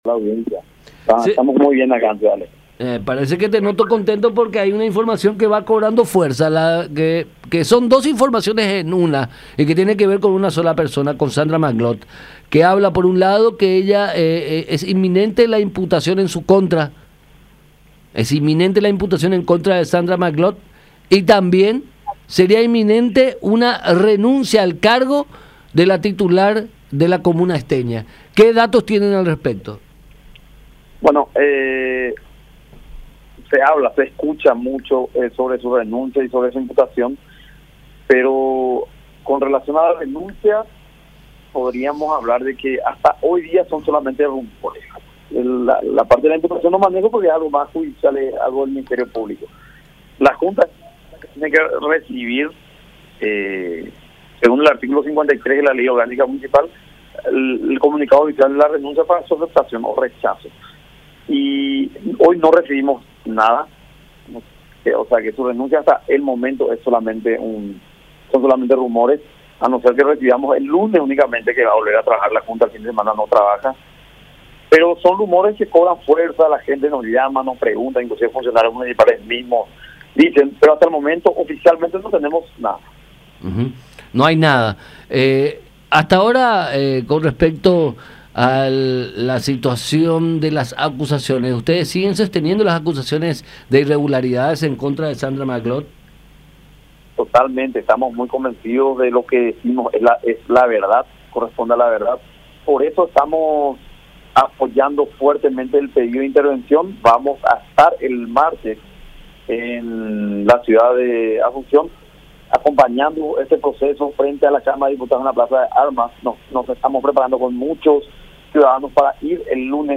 “Se habla mucho de su imputación, y en cuanto a su renuncia, son solamente rumores”, expuso el titular del legislativo esteño en contacto con La Unión.